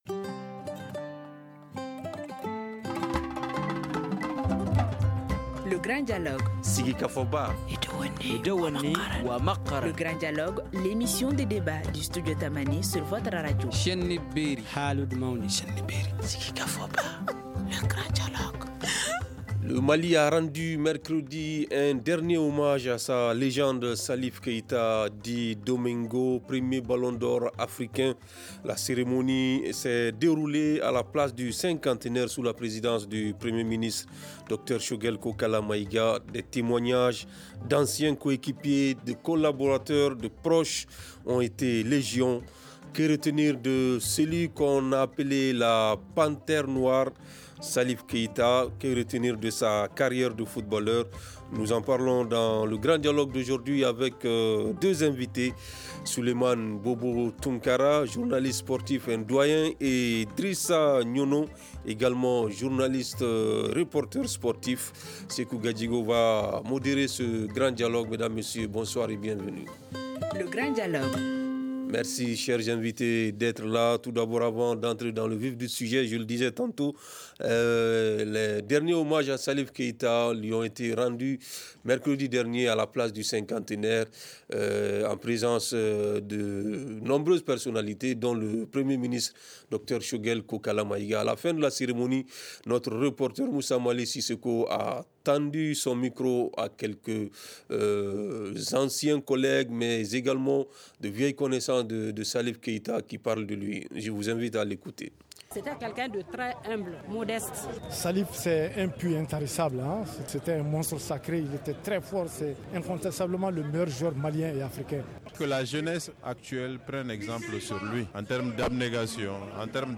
Nous en parlons dans le grand dialogue